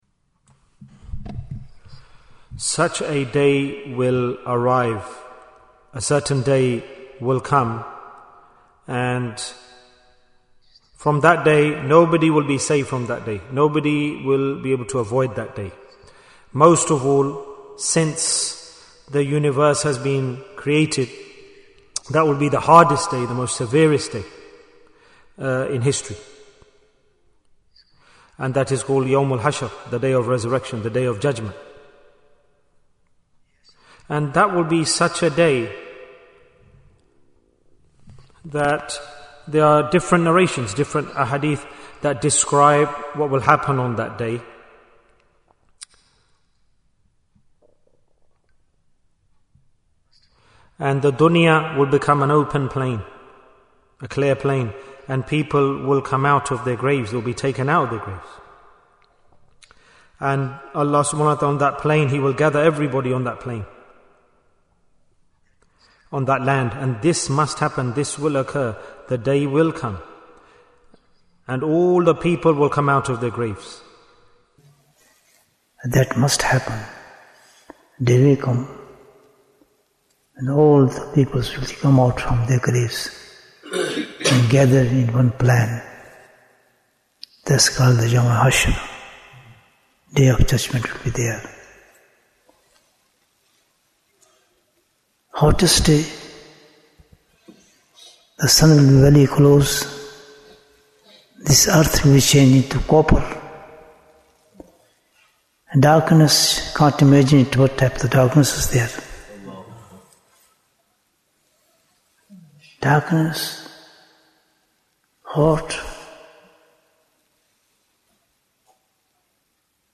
Jewels of Ramadhan 2026 - Episode 12 Bayan, 21 minutes23rd February, 2026